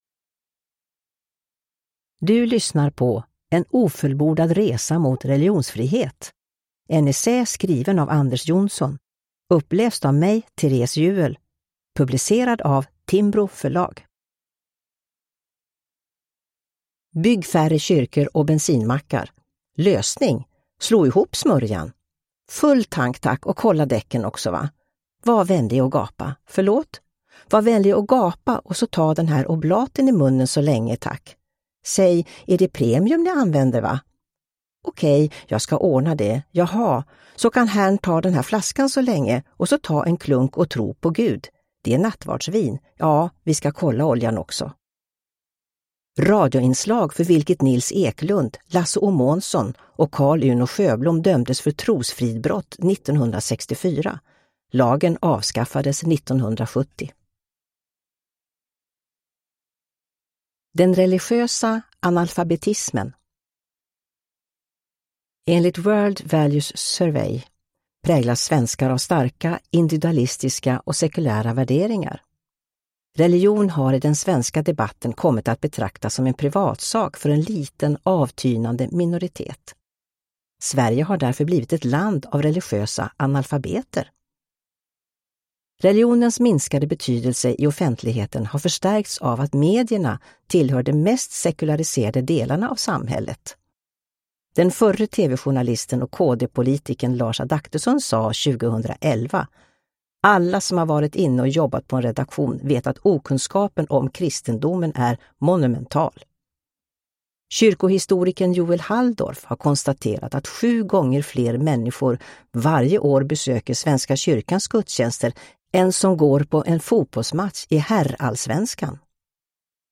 En ofullbordad resa mot religionsfrihet – Ljudbok